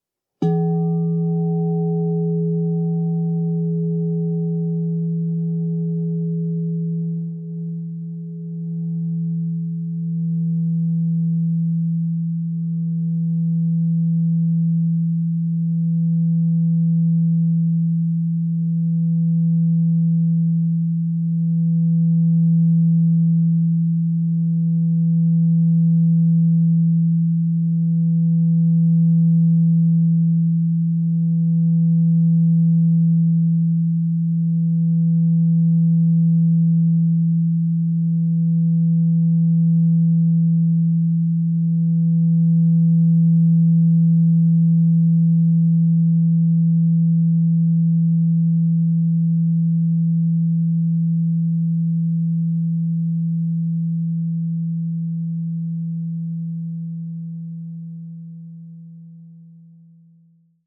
Meinl Sonic Energy 14" white-frosted Crystal Singing Bowl E3, 440 Hz, Solarplexuschakra (CSBM14E3)
Produktinformationen "Meinl Sonic Energy 14" white-frosted Crystal Singing Bowl E3, 440 Hz, Solarplexuschakra (CSBM14E3)" Die weiß-matten Meinl Sonic Energy Crystal Singing Bowls aus hochreinem Quarz schaffen durch ihren Klang und ihr Design eine sehr angenehme Atmosphäre.